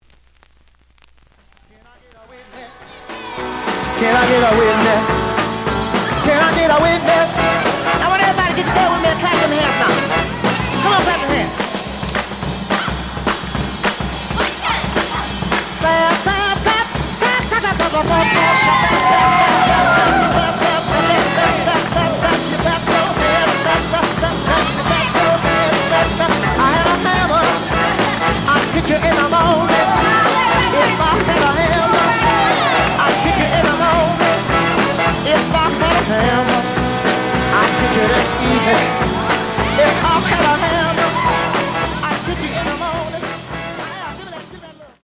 The audio sample is recorded from the actual item.
●Genre: Soul, 60's Soul
B side plays good.)